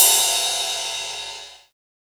VEC3 Ride
VEC3 Cymbals Ride 10.wav